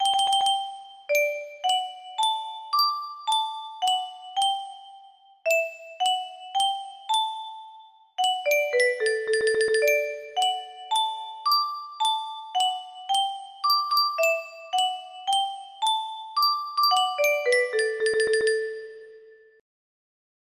A short melody. music box melody